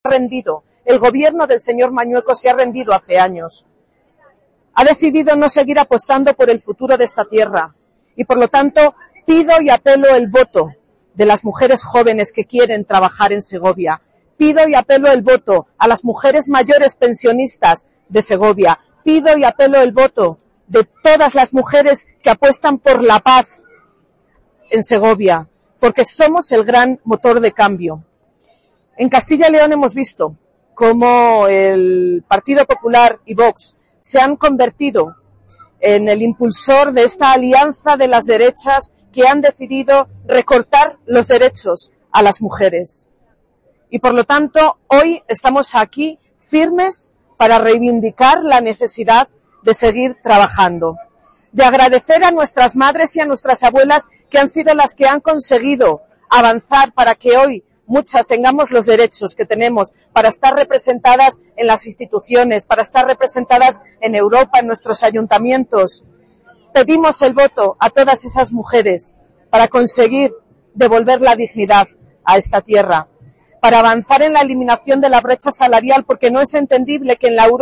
Durante su intervención, Iratxe García ha felicitado a todas las mujeres en un día “fundamental para celebrar, pero también para reivindicar”.